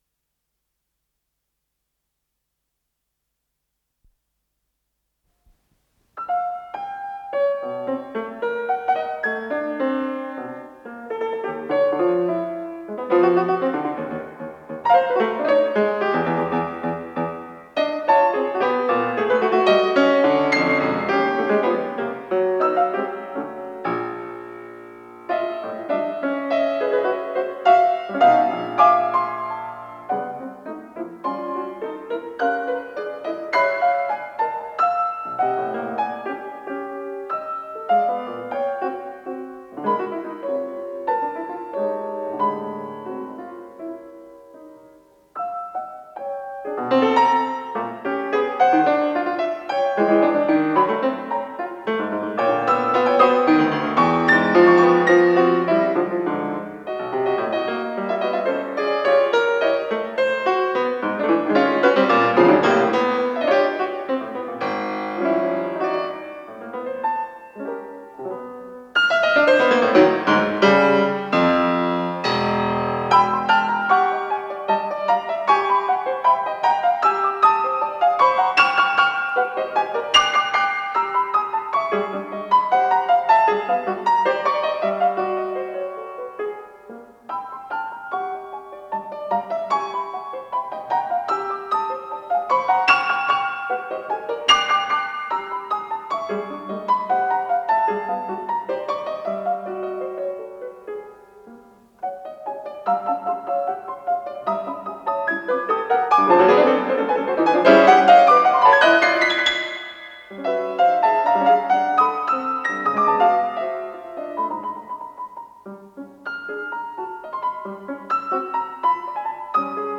ПодзаголовокДля фортепиано
ИсполнителиАлексей Любимов - фортепиано
Скорость ленты38 см/с
ВариантМоно